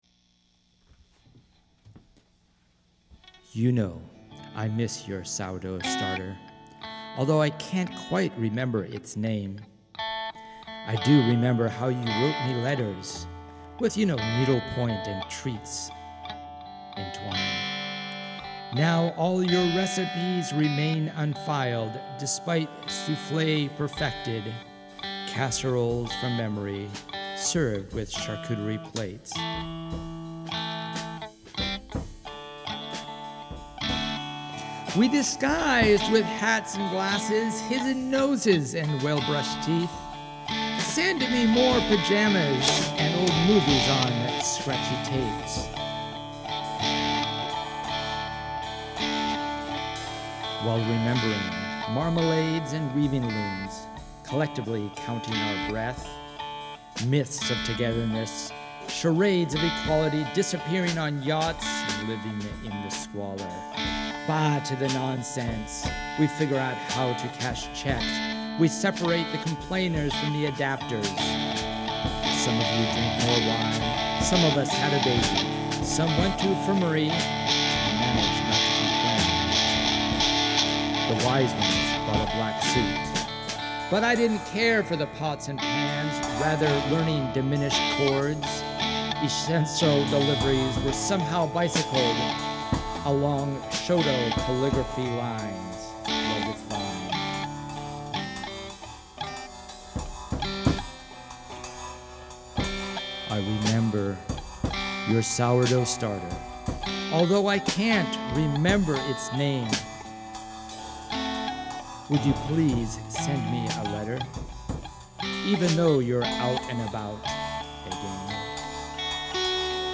{spoken song}
By / / 1 minute of reading / Spoken + Song
stereo